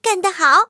M22蝉获得资源语音.OGG